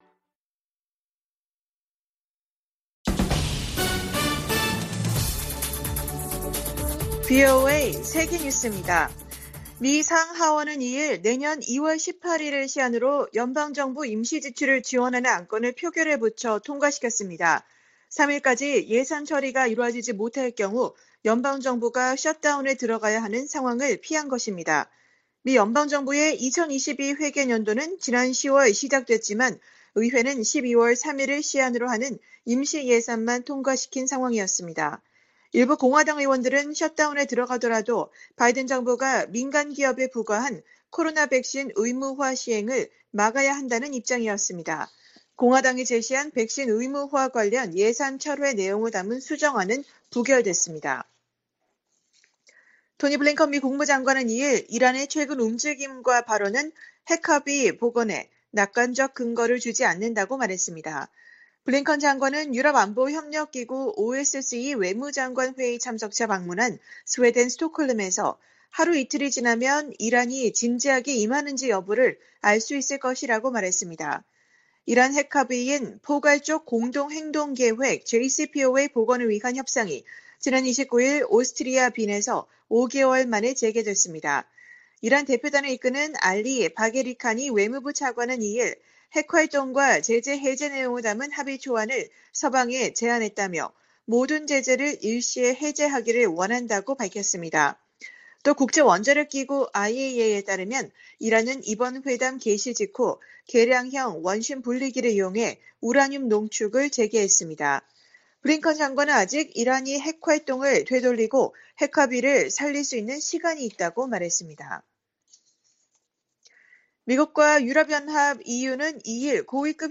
VOA 한국어 간판 뉴스 프로그램 '뉴스 투데이', 2021년 12월 3일 3부 방송입니다. 미국은 북한의 미사일 위협에 대응하기 위해 전방위 위협에 맞는 역량을 채택해야다고 미 합참차장 지명자가 말했습니다. 중국의 외교 수장인 양제츠 공산당 외교담당 정치국원이 종전선언 추진을 지지한다고 밝혔습니다. 한국의 내년 3월 대선 결과에 따라 미-한 관계와 남북 관계가 다른 양상으로 전개될 수 있다고 미국과 한국의 전문가들이 진단했습니다.